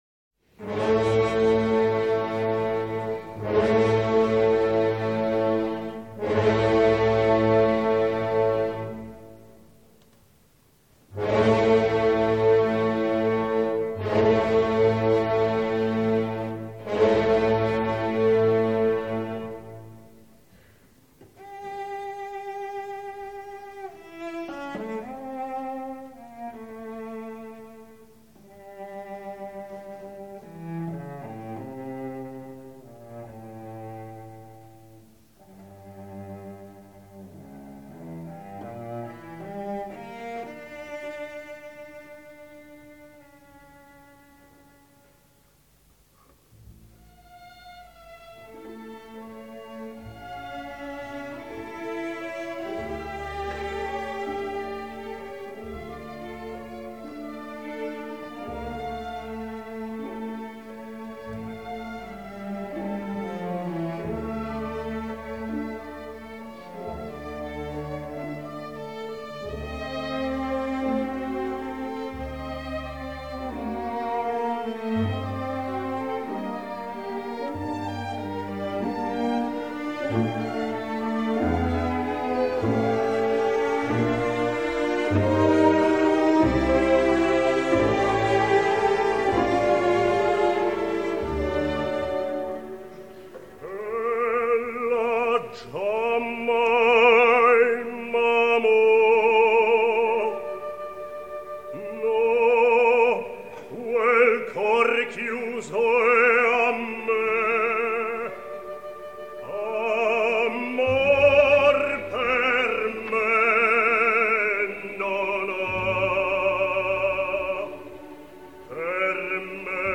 J. Van Dam, Bass-baritone; OSR